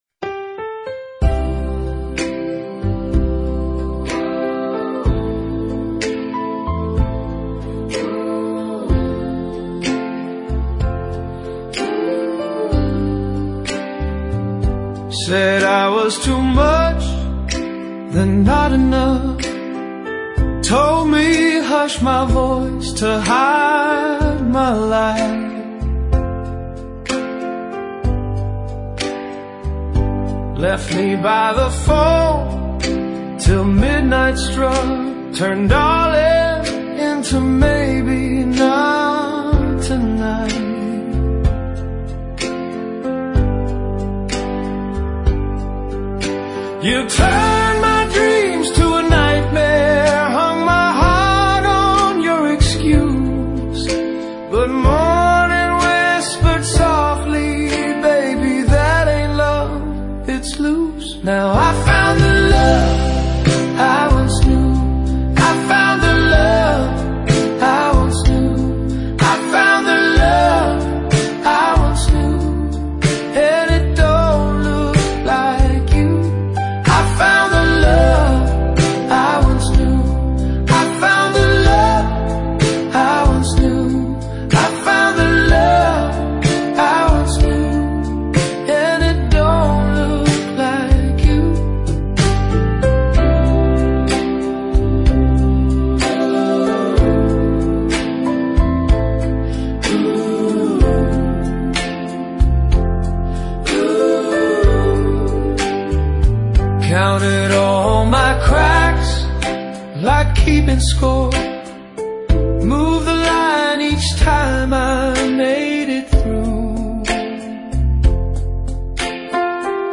Healing Retro Doo-Wop Ballad